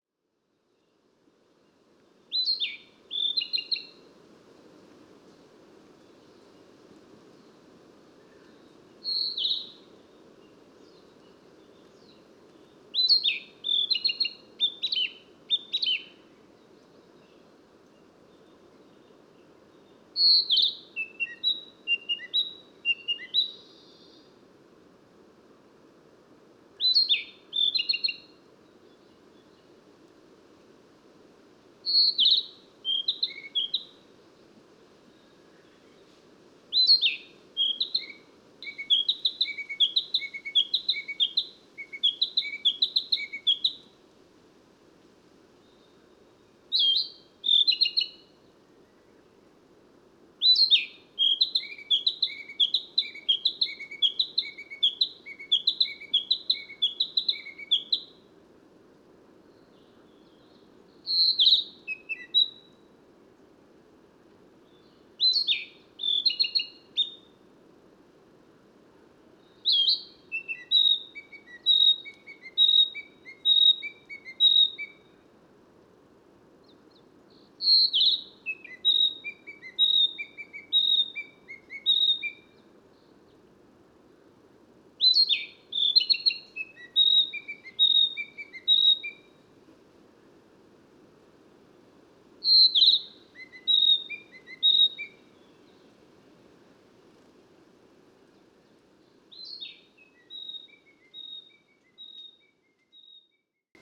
• オオハクチョウ
Edirol R-09, Telinga DAT Stereo　2009年12月30日　宮城県大崎市
声でもコハクチョウと区別できます。オオハクチョウはラッパのような響きが強いというか・・・コハクチョウは庄内平野では少ないので、この時は嬉々として、わくわくしながらマイクを向けていたのをよく覚えています。
Telingaですがパラボラをはずしています。低域ノイズが少ないのでハイパスフィルターはオンにしているはず。オフにしたほうがオオハクチョウらしい響きがもっとあったんじゃないかな。